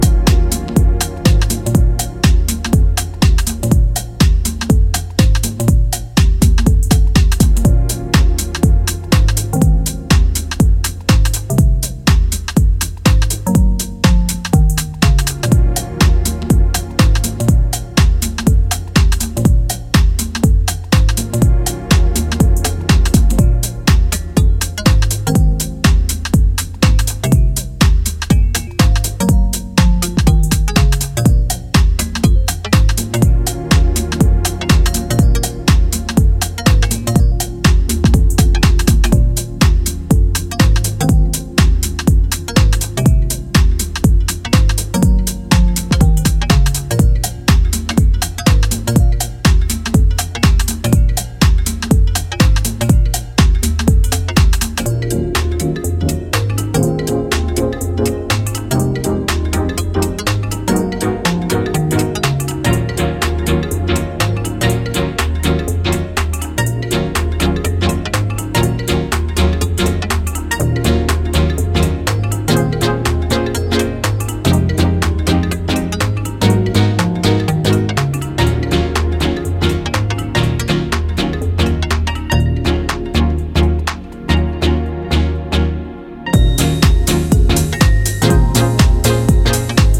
バウンスぎみのベースに気持ち良いメロディアスなシンセサイザーに魅了されるスペシャルなトラック・ワークは流石！
ジャンル(スタイル) DEEP HOUSE